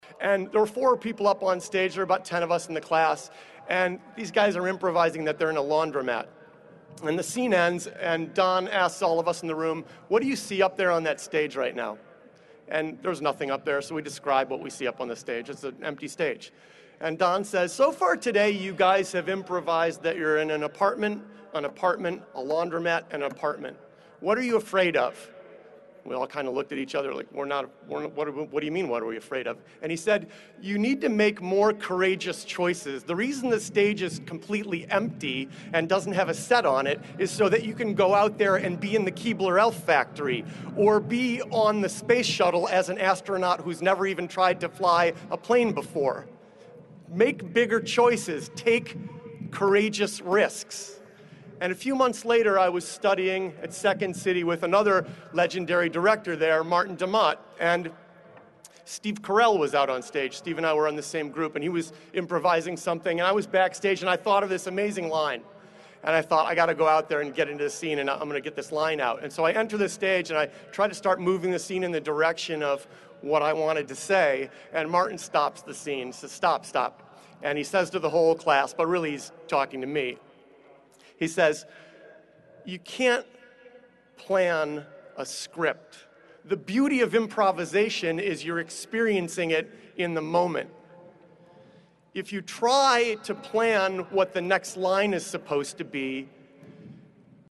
在线英语听力室公众人物毕业演讲第326期:推特CEO迪克2013密歇根大学(3)的听力文件下载,《公众人物毕业演讲》精选中西方公众人物的英语演讲视频音频，奥巴马、克林顿、金庸、推特CEO等公众人物现身毕业演讲专区,与你畅谈人生。